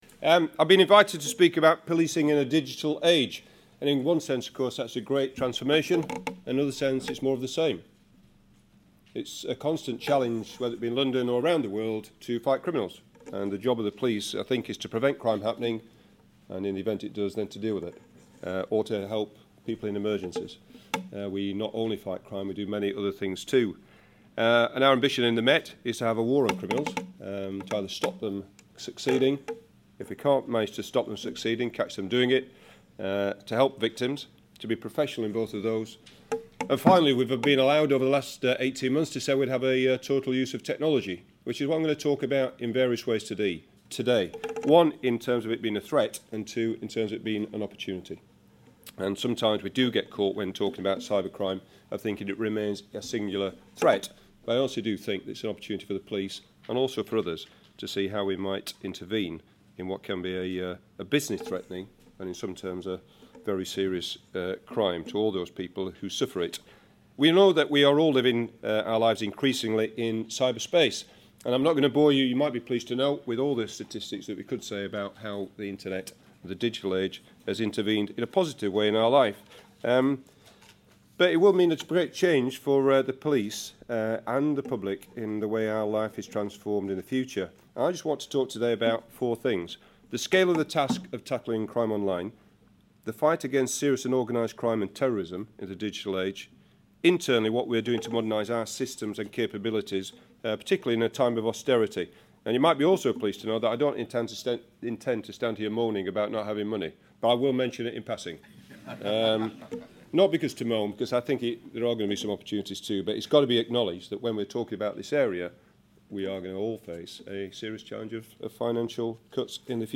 Sir Bernard Hogan-Howe QPM, Commissioner of the Met Police and the UK's most senior police officer, sets out the challenges and opportunities of policing, as traditional crime falls and digital criminals come to the fore. Speaking to members of London First's Security and Resilience Network, he set out the scale of the threat and the practical challenges to the police, but he also stressed the great opportunities digital offers in the fight against crime.